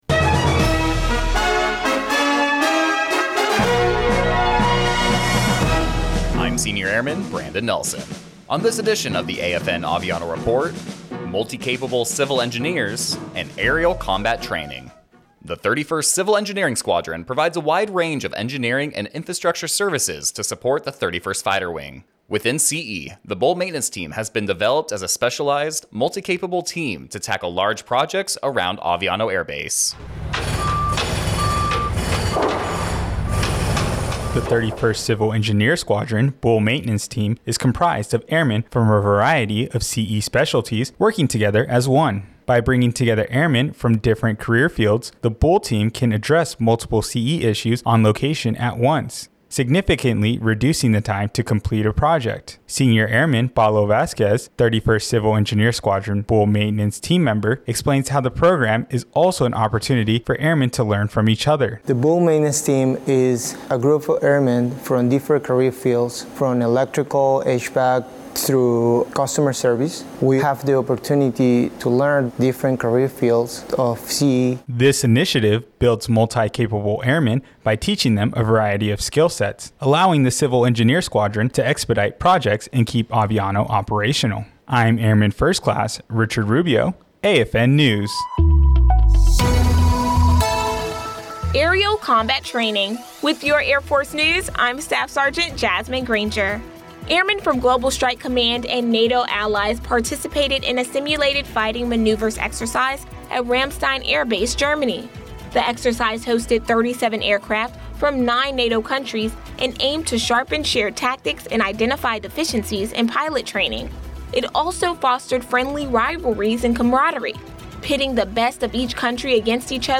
AFN Aviano Radio News: Multi-Capable Civil Engineers
American Forces Network (AFN) Aviano radio news reports the 31st Civil Engineer Squadron Bull Maintenance Team, a specialized, multi-capable team designed to tackle large projects around Aviano Air base by bringing together airmen from a variety of career fields.